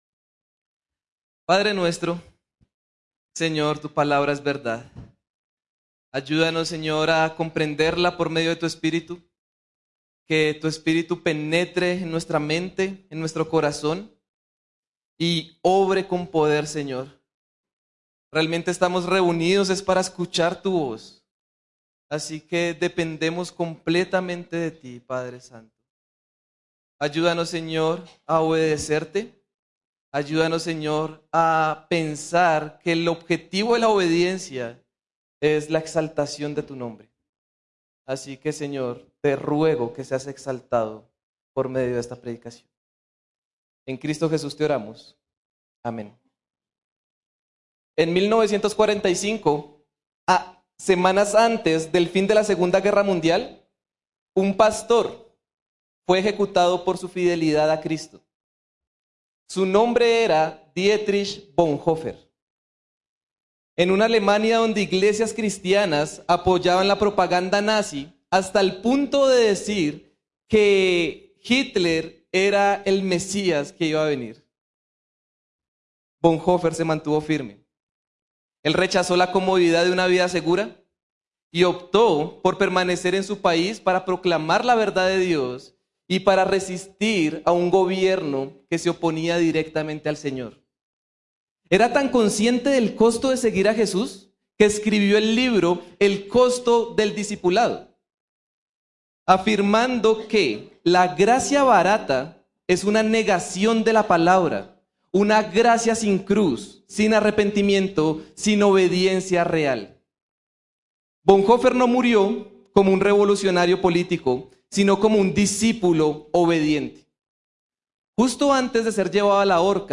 Sermons – Iglesia Bautista Renacer Bogotá